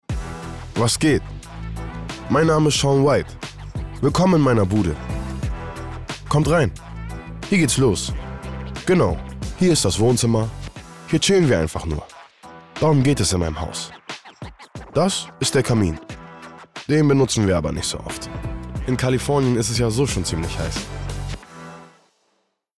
markant, dunkel, sonor, souverän, plakativ
Mittel minus (25-45)